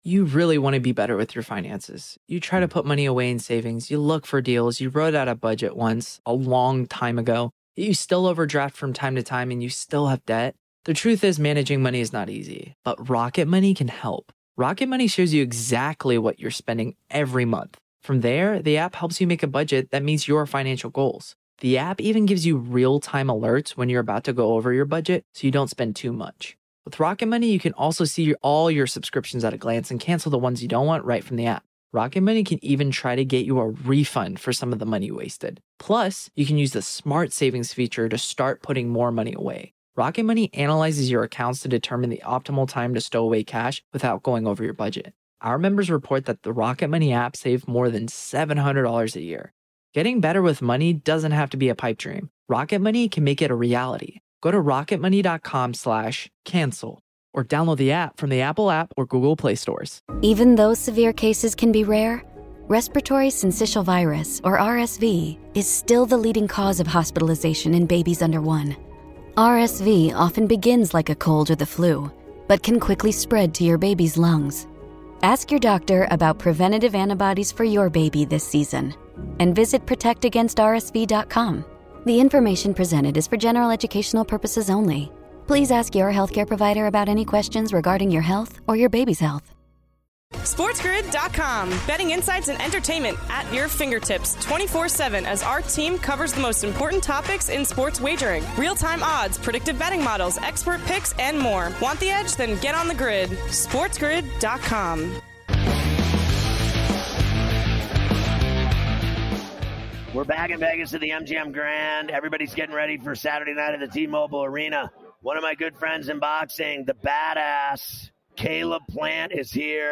9/12 Hour 2: Live from Las Vegas at Canelo vs Berlanga Media Row
Ferrall live from Las Vegas at Canelo vs Berlanga Media Row welcomes special guests: Caleb Plant, Edgar Berlanga, Jerry Izenberg, and more!
Legendary sports shock jock Scott Ferrall takes the gaming world by storm with his “in your face” style, previewing the evening slate of games going over lines, totals and props, keeping you out of harms way and on the right side of the line.